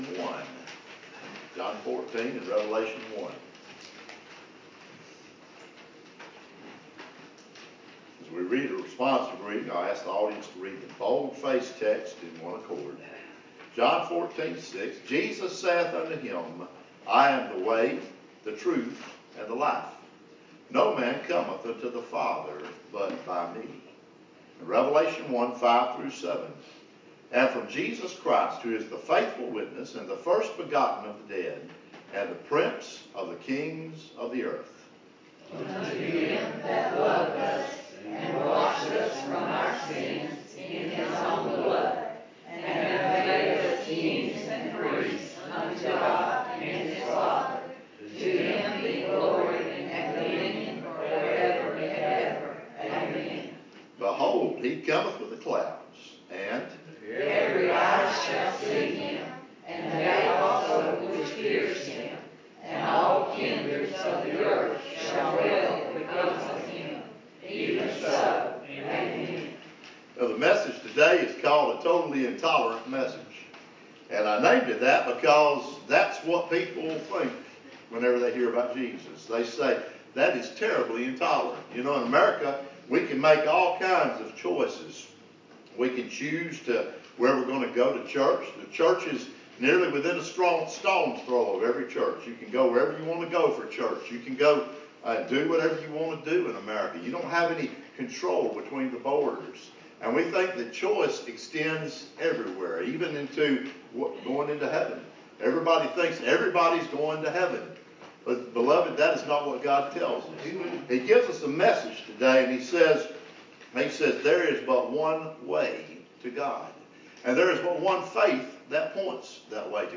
An Intolerant Message (On Communion Sunday)